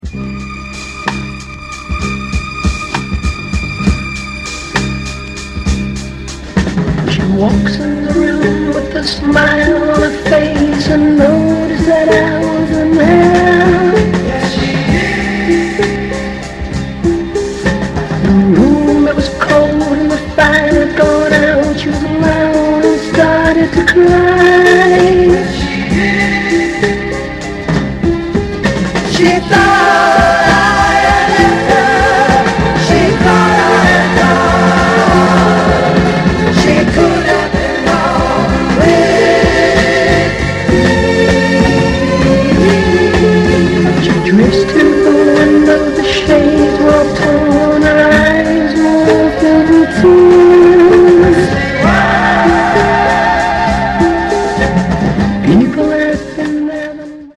And it’s all about the psyched out B-side!